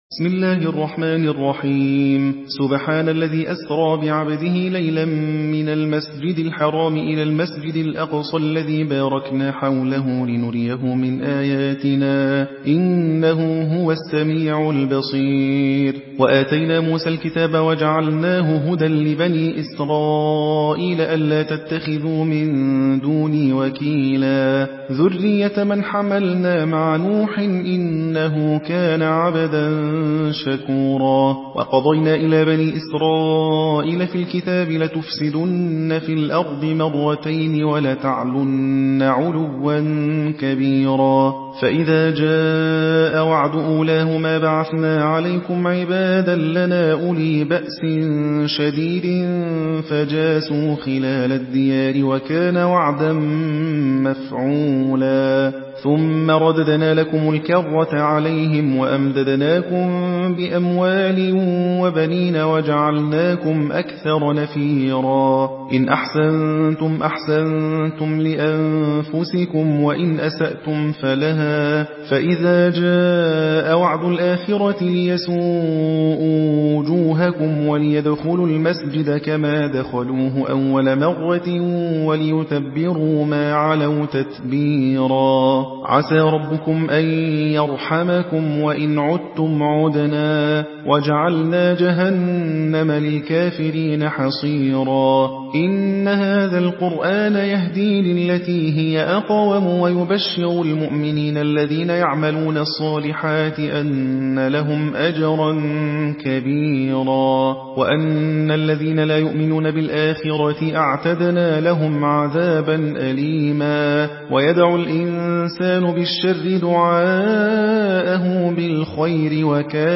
حدر